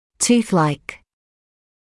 [‘tuːθlaɪk][‘туːслайк]зубоподобный, похожий на зуб